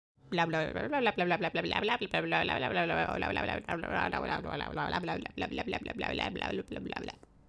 female_blah.wav